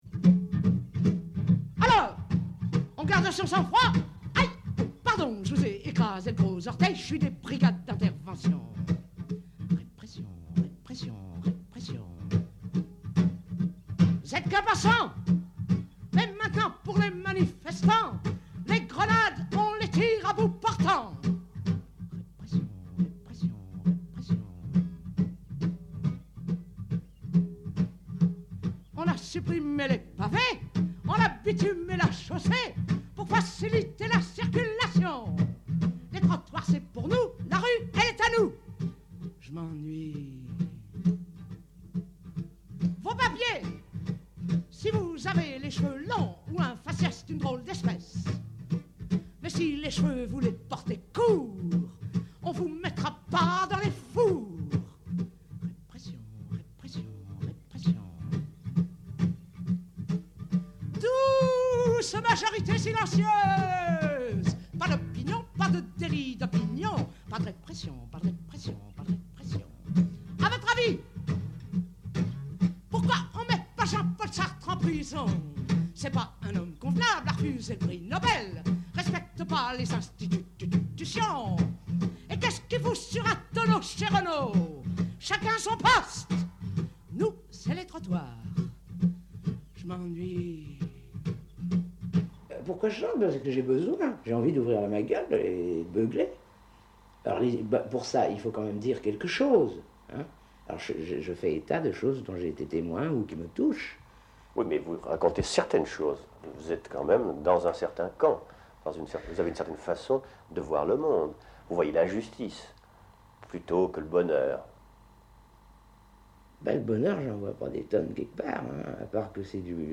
仏蘭西で、1958年ごろからギター１本のブルーズ歌手